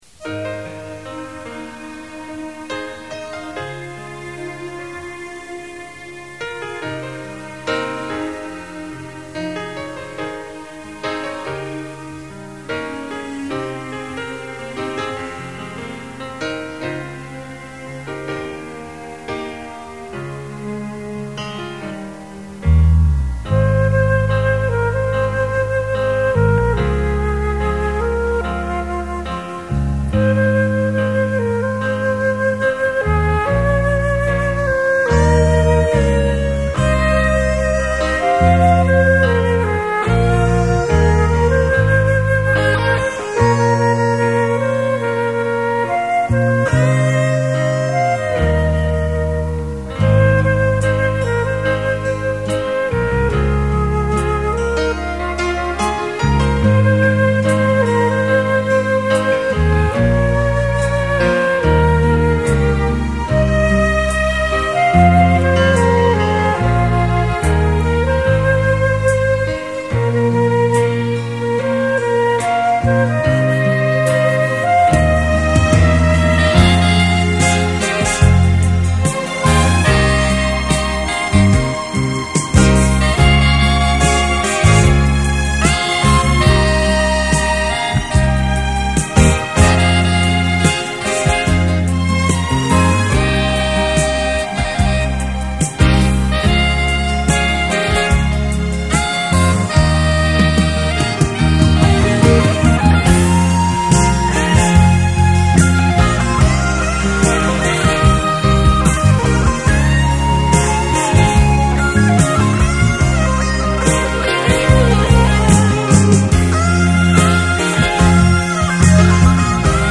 缠绵的慢四步曲集
电子钢琴 长笛 两只萨克斯 电吉他 提琴组